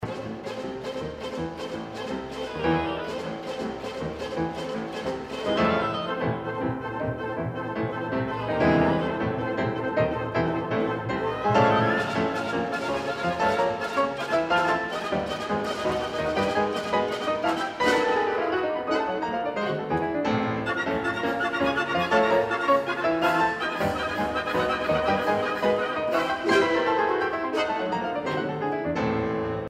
原速度，乐队以三连音符伴奏，钢琴穿梭于伴奏之间